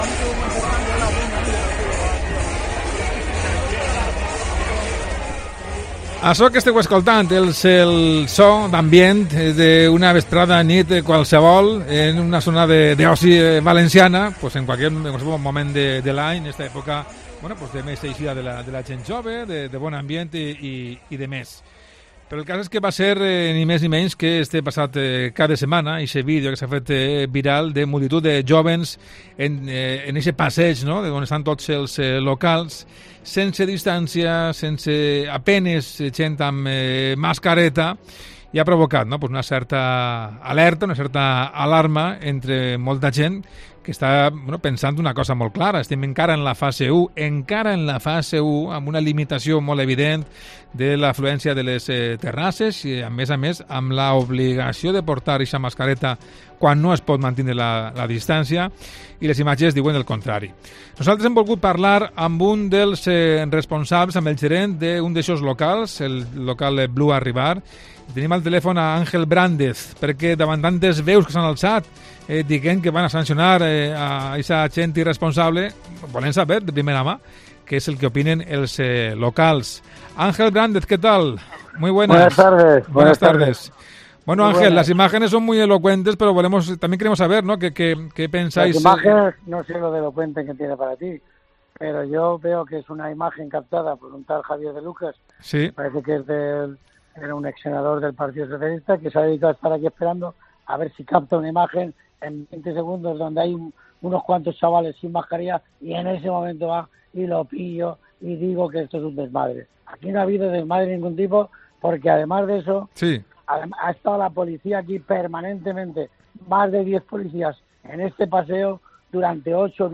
Gerente de uno de los locales de la Marina de Valencia